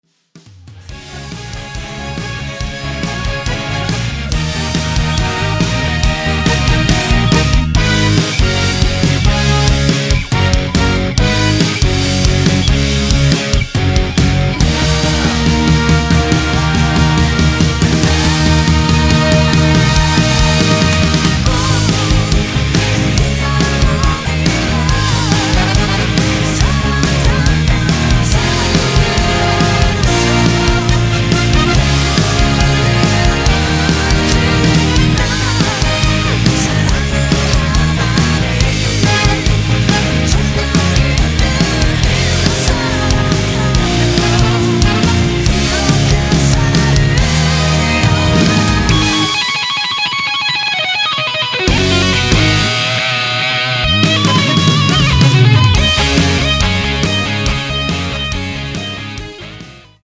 원키 코러스 포함된 MR입니다.
Ab
앞부분30초, 뒷부분30초씩 편집해서 올려 드리고 있습니다.
중간에 음이 끈어지고 다시 나오는 이유는